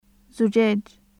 1.3.4. 有声・歯茎・摩擦音/z/
日本語の「ザ」や「ズ」と同じ/z/の音です。
زجاج /zujaːj/ ガラス